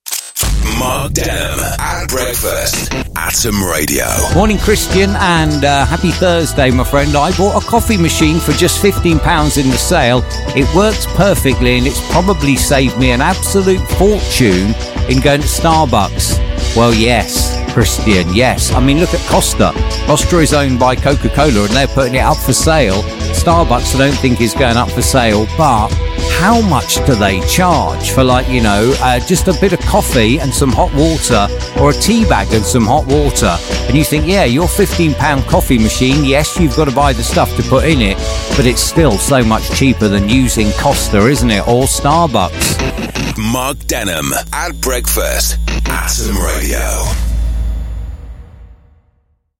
Listeners from Slough, Windsor & Maidenhead share their craziest steals.